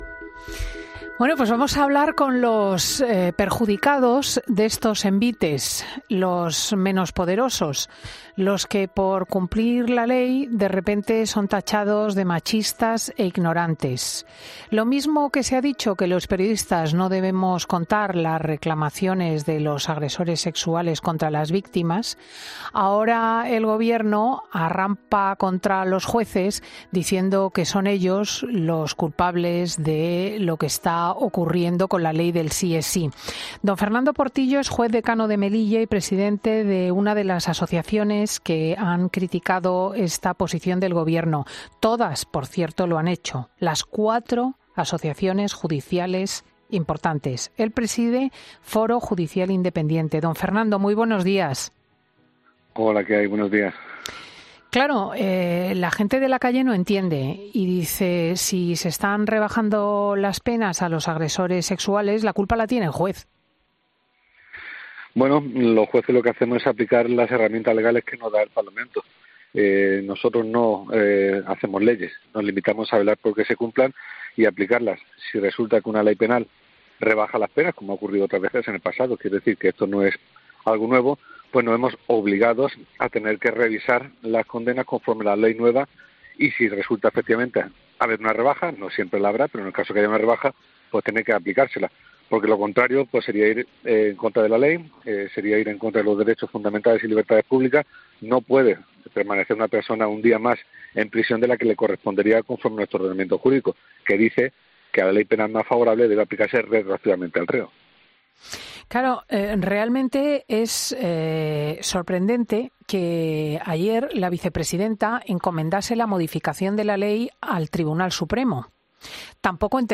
En 'Fin de Semana' hemos hablado con Fernando Portillo, juez decano de Melilla y el presidente del Foro Judicial Independiente, que ha defendido la imparcialidad judicial